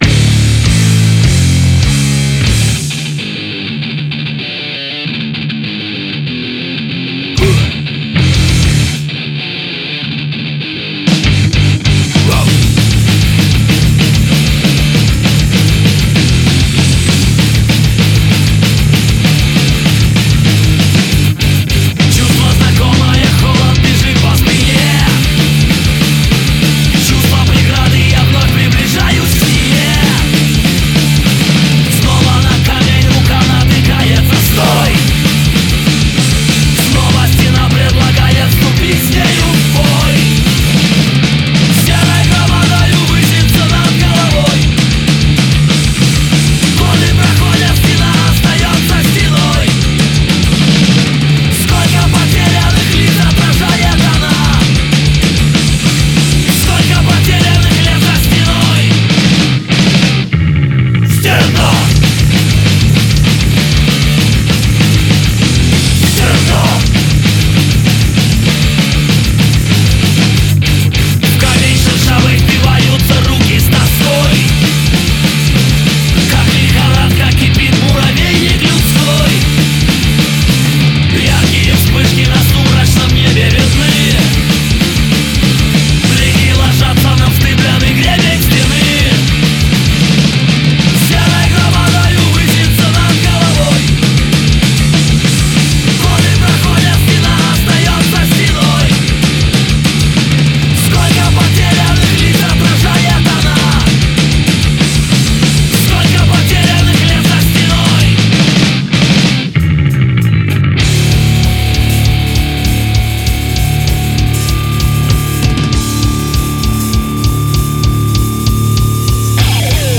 кавер-версия песни
вокал, гитара
бас, вокал
барабаны, вокал
гитара, вокал